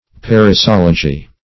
perissology - definition of perissology - synonyms, pronunciation, spelling from Free Dictionary
Search Result for " perissology" : The Collaborative International Dictionary of English v.0.48: Perissology \Per`is*sol"o*gy\, n. [L. perissologia, Gr.